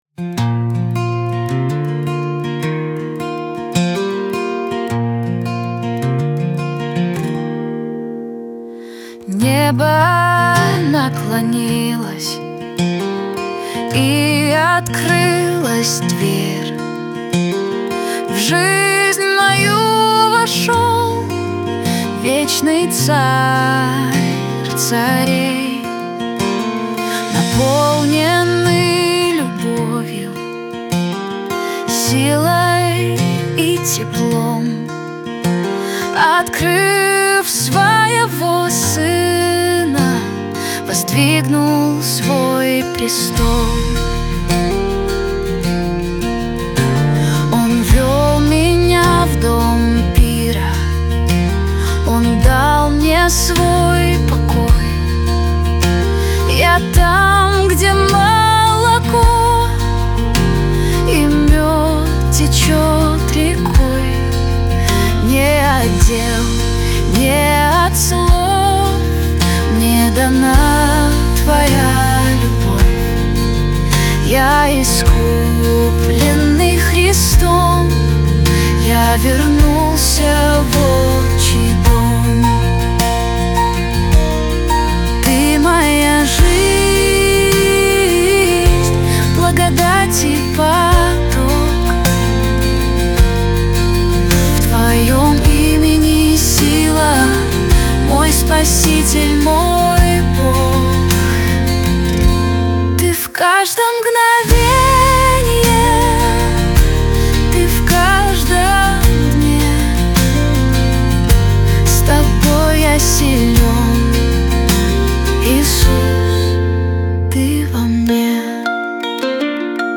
песня ai
127 просмотров 105 прослушиваний 5 скачиваний BPM: 79